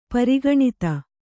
♪ pari gaṇita